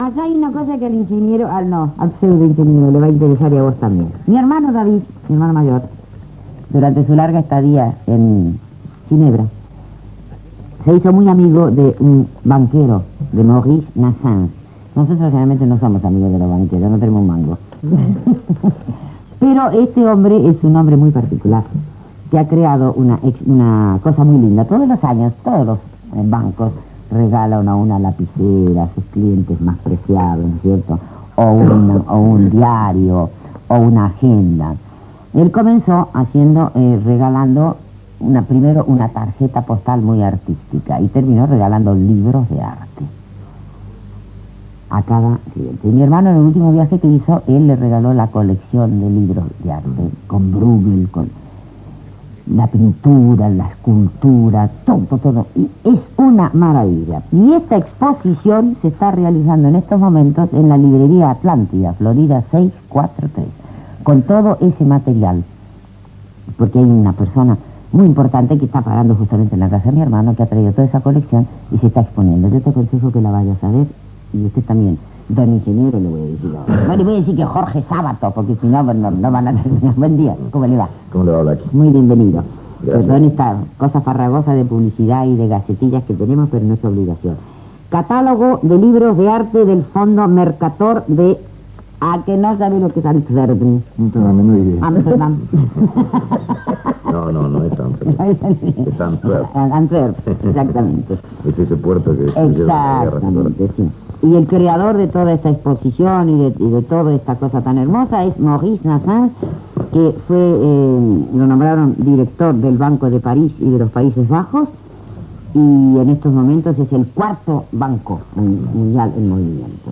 La presentadora Blackie, más conocida como Paloma Efron, periodista y conductora pionera de la radio y televisión argentina, entrevista a Jorge A. Sabato en su programa radial.